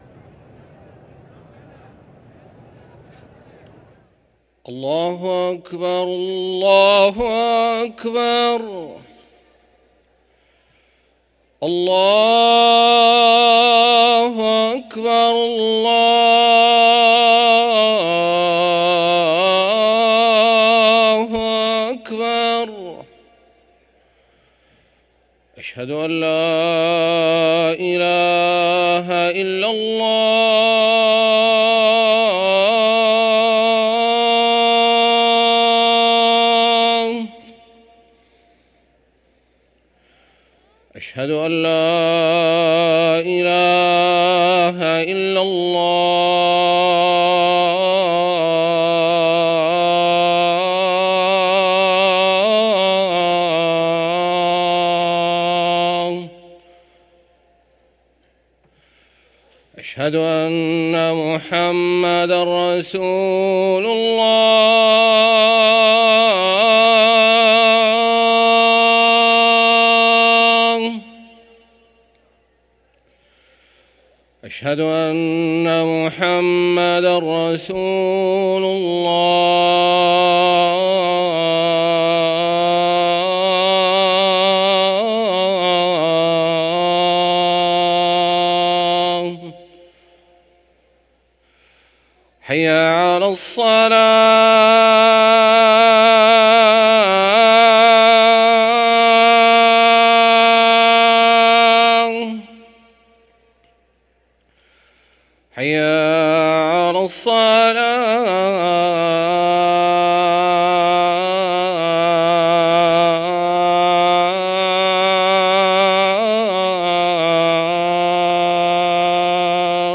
أذان العشاء للمؤذن أحمد نحاس الجمعة 24 جمادى الأولى 1445هـ > ١٤٤٥ 🕋 > ركن الأذان 🕋 > المزيد - تلاوات الحرمين